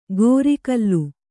♪ gōri kallu